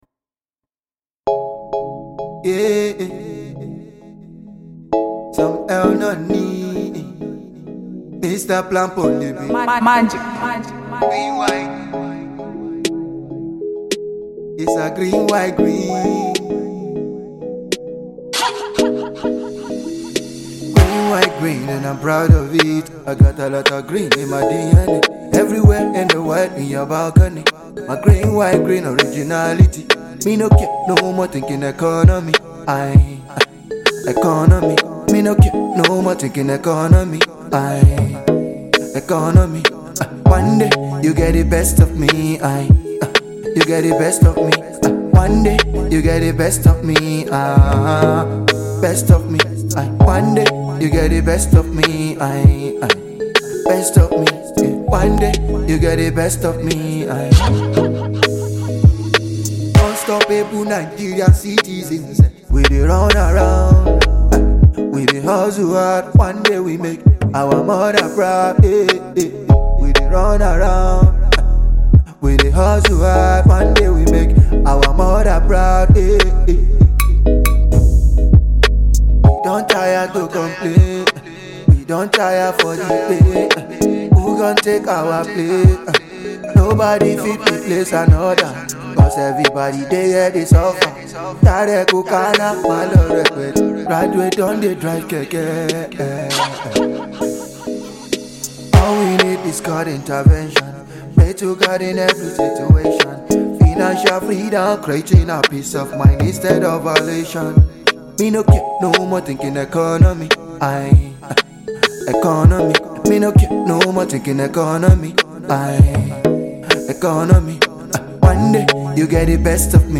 Inspirational singer